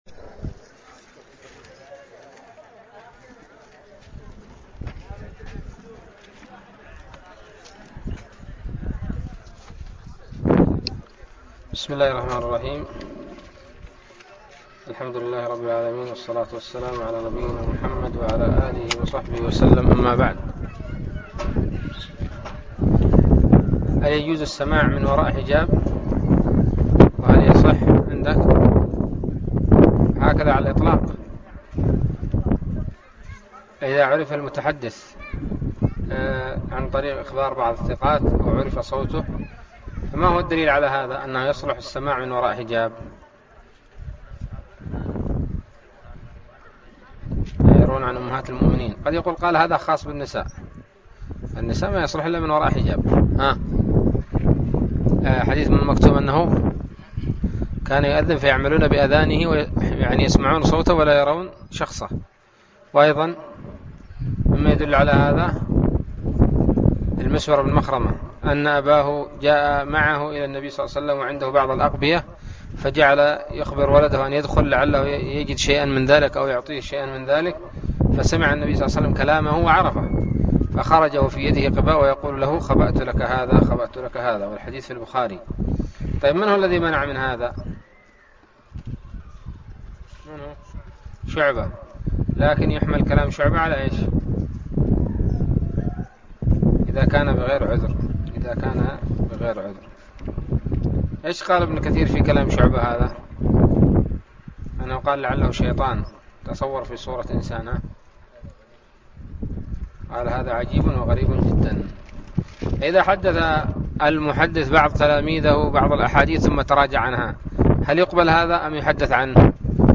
الدرس التاسع والثلاثون من الباعث الحثيث